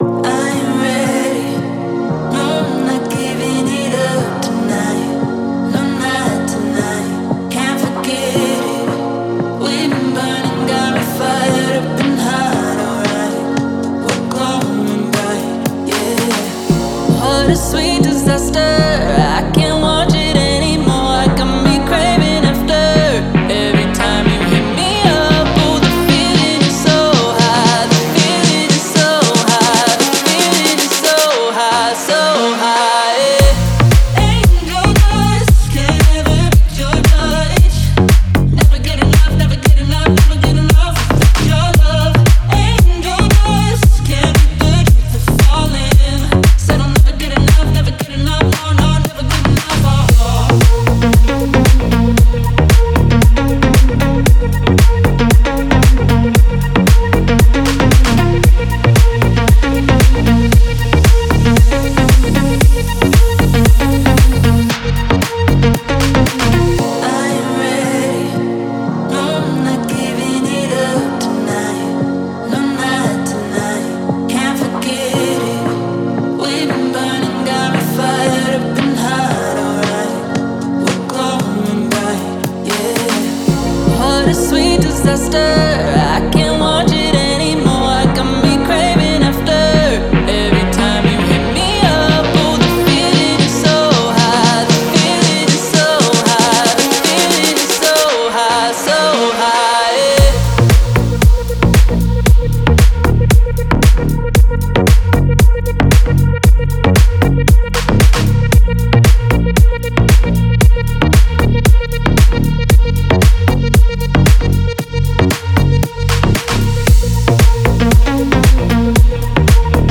Текст песни Музыка ДИНАМИЧНАЯ МУЗЫКА